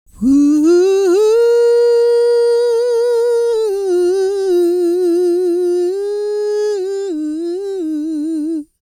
E-CROON 201.wav